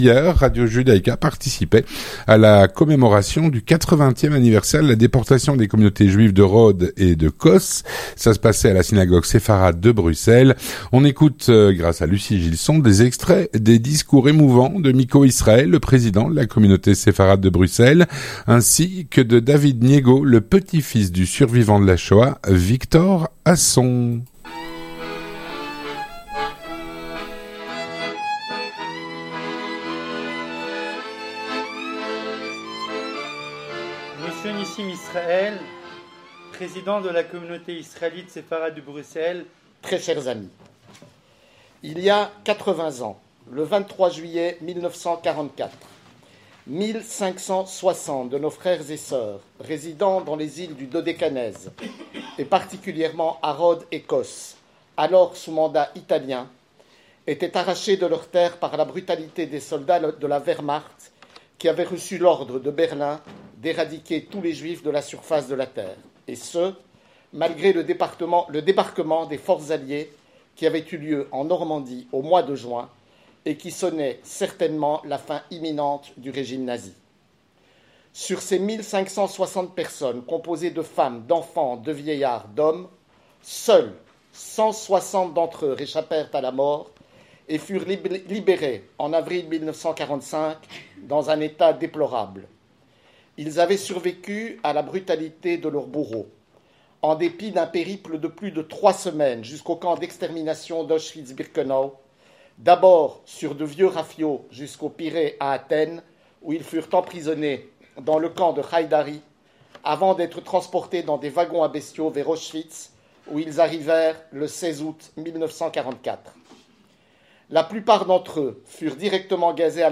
Hier, Radio Judaica participait à la Commémoration du 80ème anniversaire de la déportation des communautés juives de Rhodes et de Kos à la synagogue Sépharade de Bruxelles.